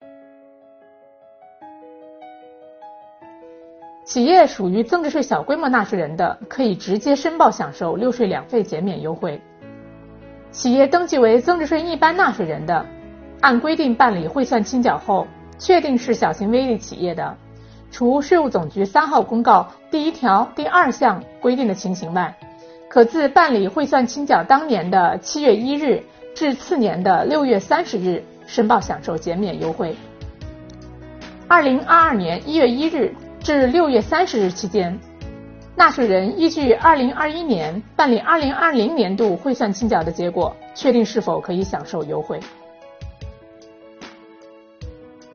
本期课程由国家税务总局财产和行为税司副司长刘宜担任主讲人，解读小微企业“六税两费”减免政策。今天，我们一起学习：小型微利企业如何申报享受“六税两费”优惠？